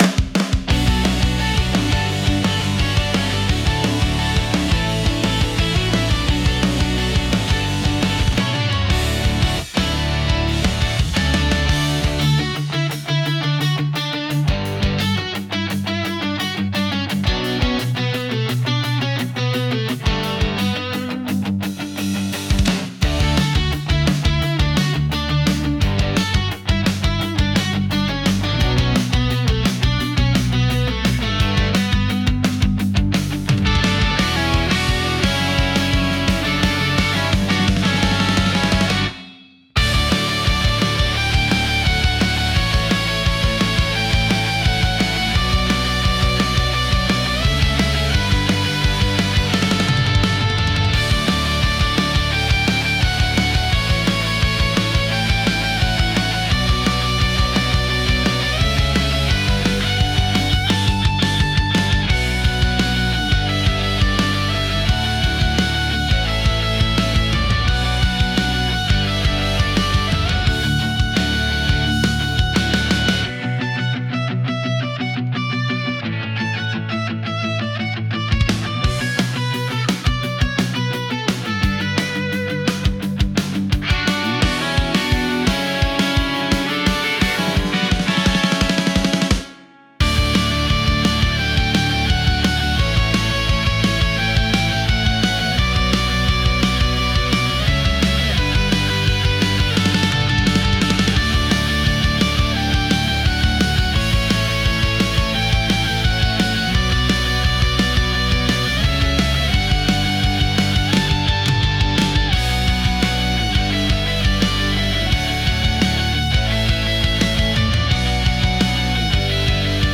Genre: Pop Punk Mood: High Energy Editor's Choice